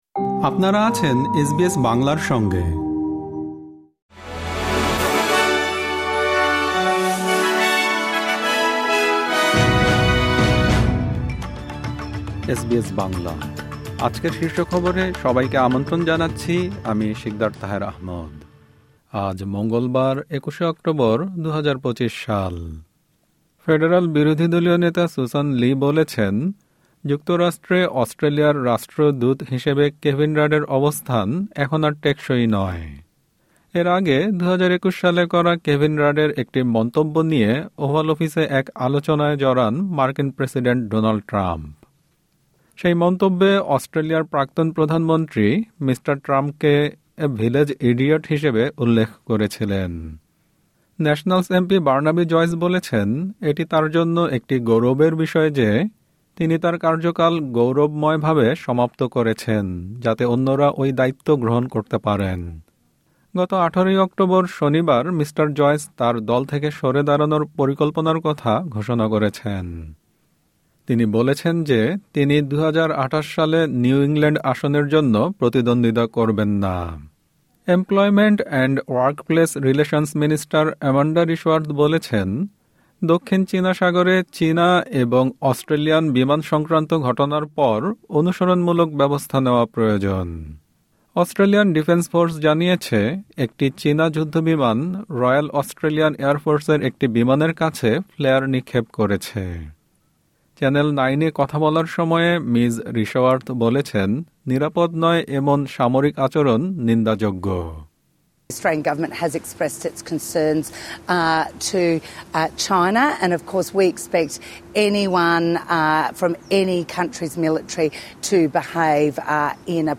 এসবিএস বাংলা শীর্ষ খবর: ২১ অক্টোবর, ২০২৫